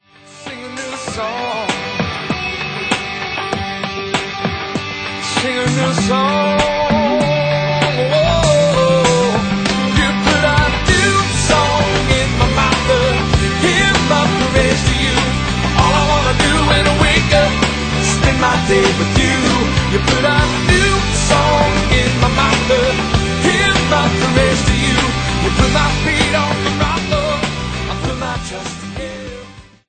This passionate live recording
traditional & modern worship songs & hymns of faith